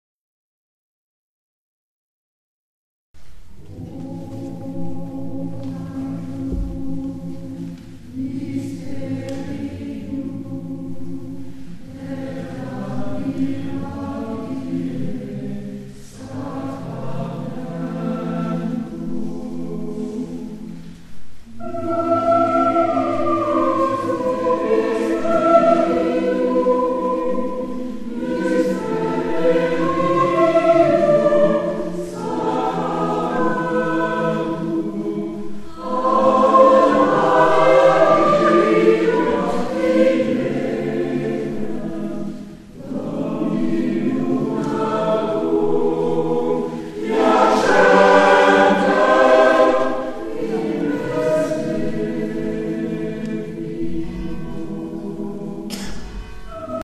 EXTRAITS MUSICAUX, sons et vidéos, en concerts ou répétitions ..
O Magnum 20 décembre 2011 Quatre voix mixtes a cappella